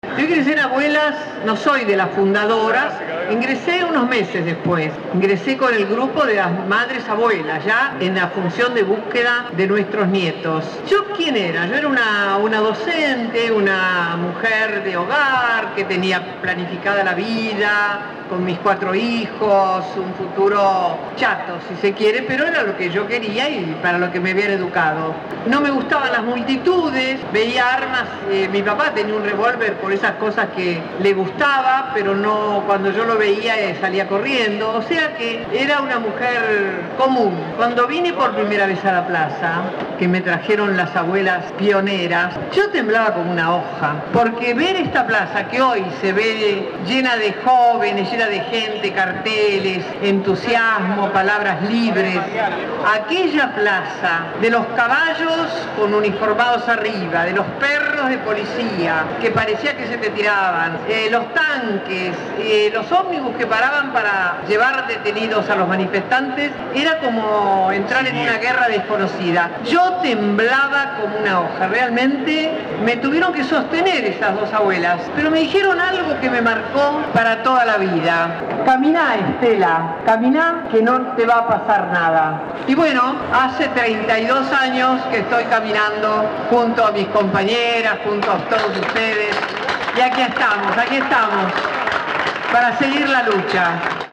Todos los que hacemos Radio Gráfica estamos muy contentos por haber sido invitados a participar, en el marco de la radio abierta, de la 29º Marcha de la Resistencia encabezada por Madres de Plaza de Mayo linea fundadora, junto a decenas de organizaciones adherentes.
Recordemos que la misma se realiza desde el 10/12/1981 en la Plaza de Mayo.